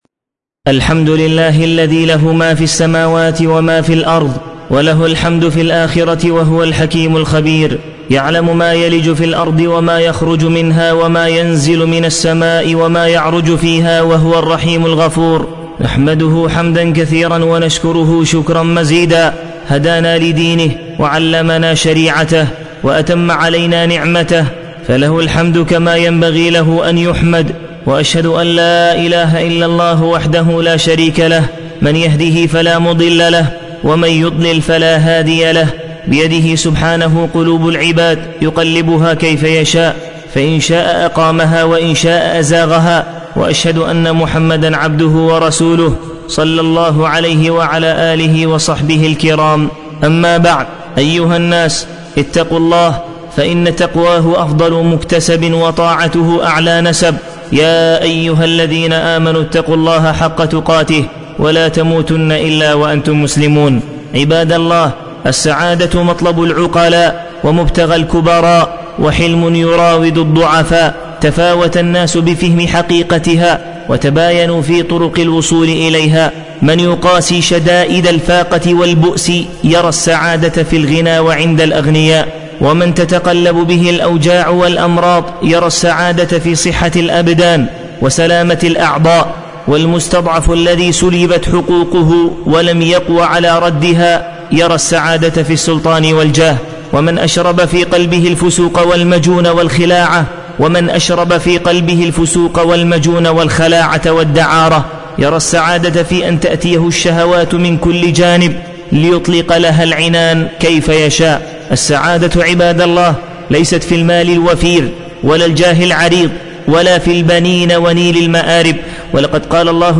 خطبة الجمعة بعنوان السعادة